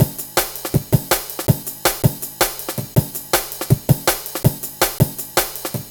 Dinky Break 05-162.wav